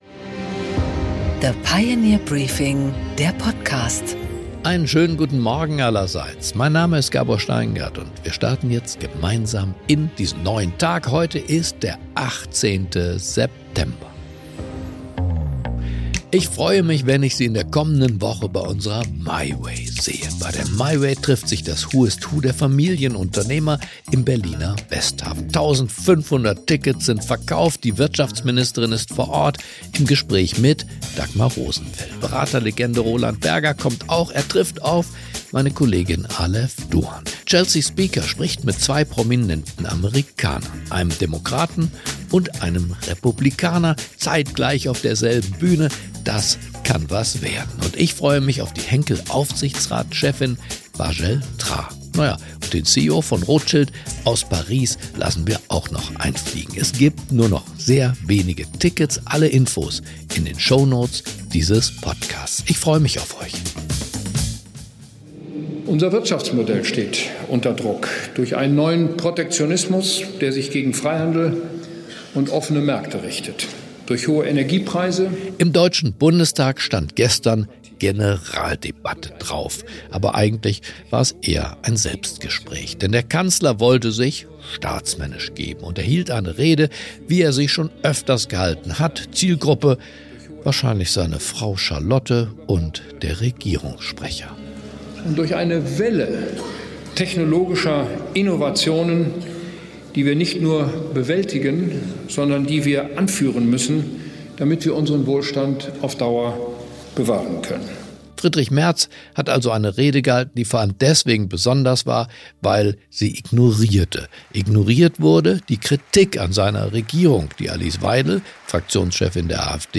Gabor Steingart präsentiert das Pioneer Briefing
Interview